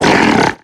Cri de Monaflèmit dans Pokémon X et Y.